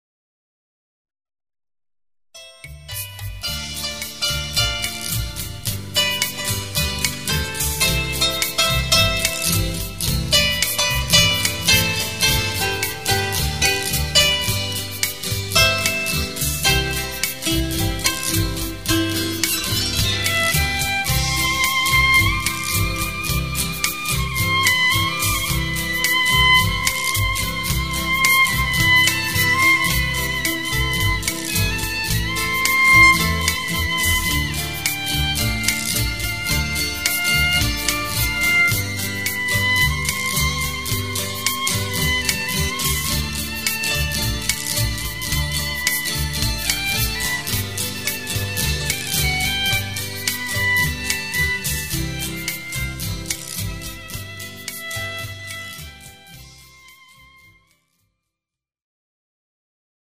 SWING AND JAZZ QUARTET